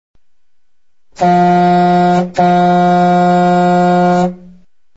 إستماع وتحميل مجموعة نغمات منوعة (mp3) رنات للجوال (mobile ringtone download) صوت الباخرة – نغمات للجوال the ship sound ringtones.
1 – صوت الباخرة ( Trumpet ship )